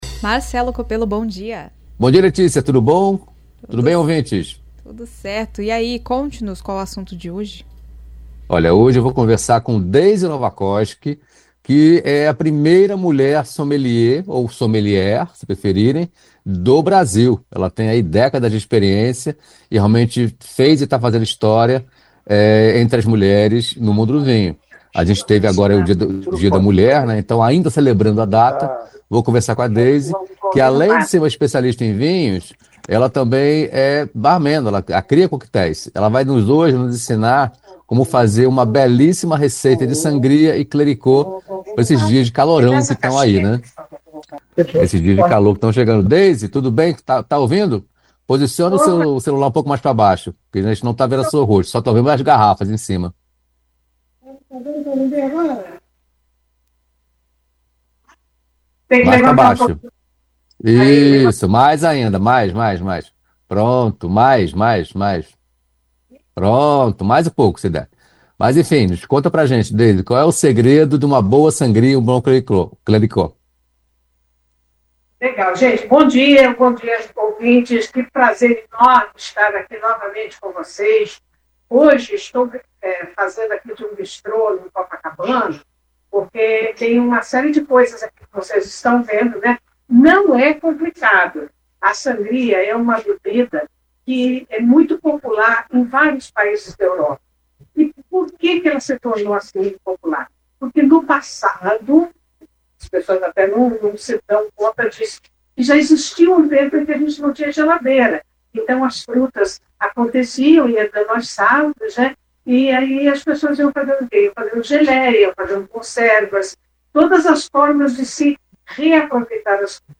Sommelier ensina como preparar sangria e clericot para os dias quentes